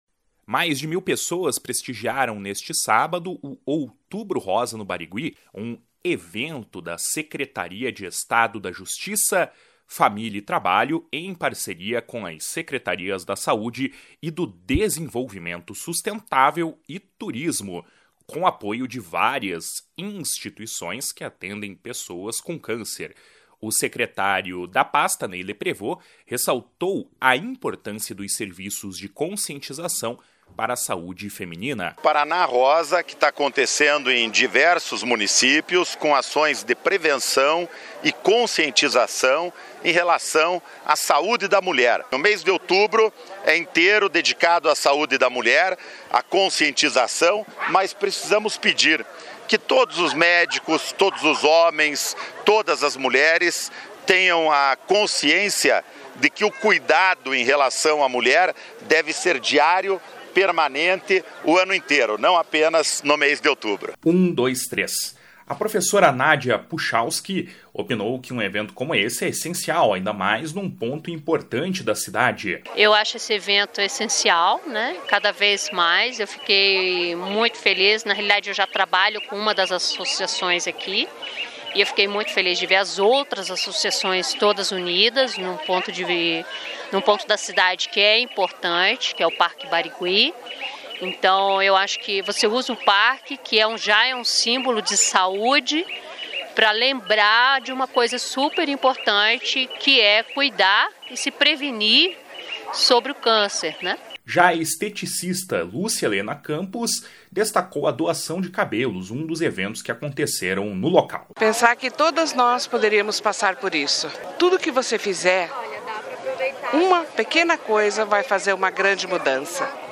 O secretário da pasta, Ney Leprevost, ressaltou a importância dos serviços de conscientização para a saúde feminina. // SONORA NEY LEPREVOST //